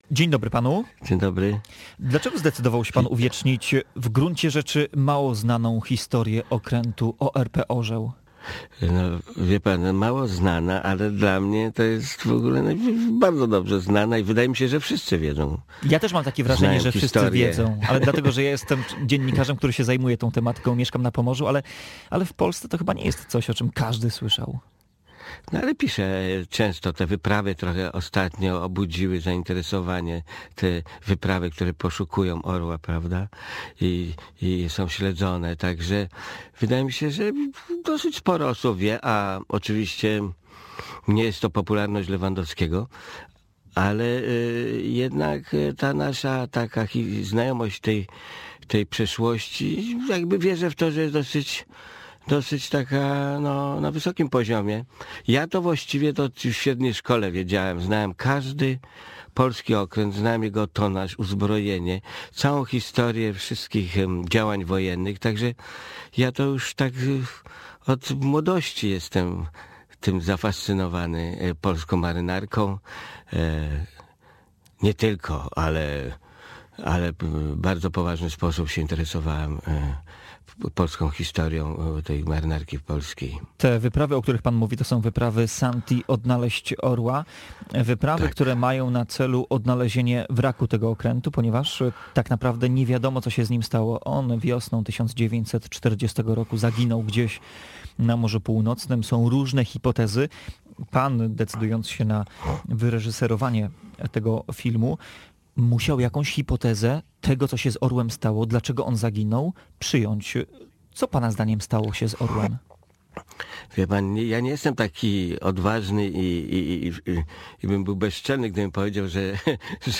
W 101. rocznicę utworzenia Marynarki Wojennej Gościem Dnia Radia Gdańsk był Jacek Bławut, reżyser filmu „Orzeł. Ostatni Patrol”, który poświęcony jest historii polskiego okrętu podwodnego. „Orzeł” w niewyjaśnionych okolicznościach zaginął wiosną 1940 roku na Morzu Północnym.